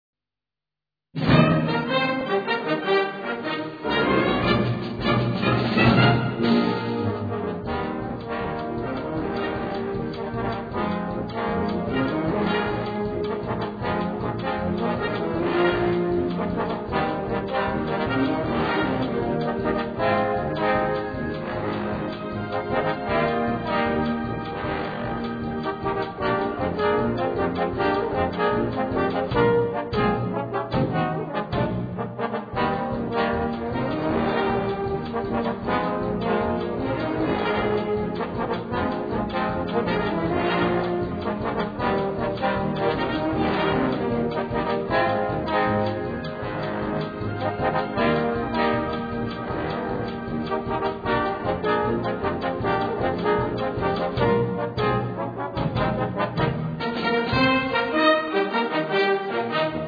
Gattung: Solostück
Besetzung: Blasorchester
Ein fröhliches Posaunentrio.